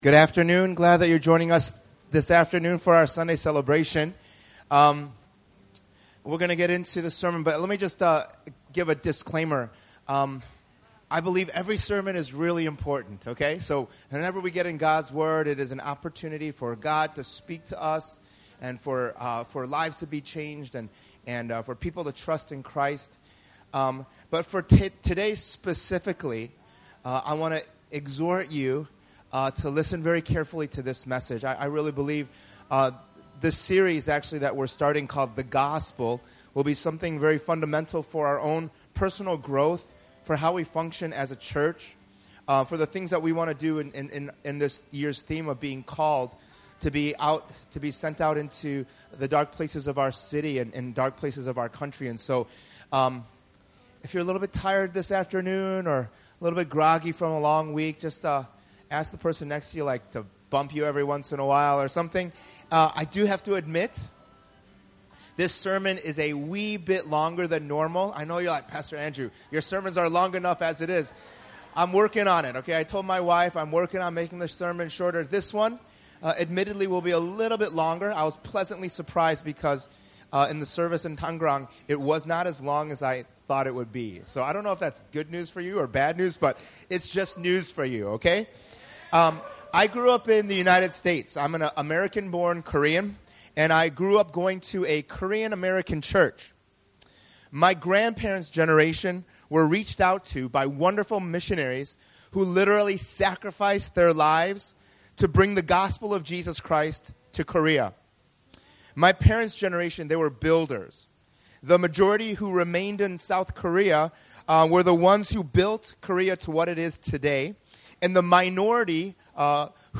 In this three-part sermon series, we’ll see how the Gospel fits into God’s redemptive history in Part 1: The Full Picture, and then what the Gospel does in us in Part 2: A New Heart, and finally how the Gospel works through us in Part 3: Good Works.